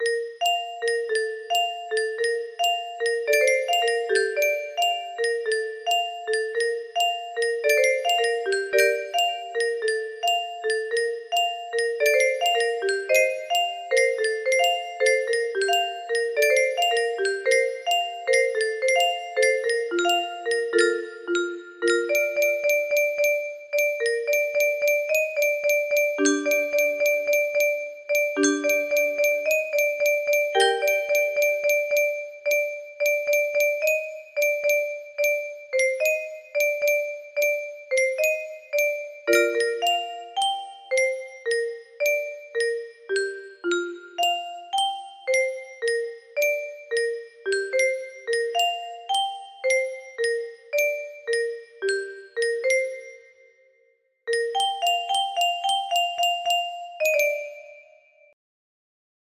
boaf pt1 music box melody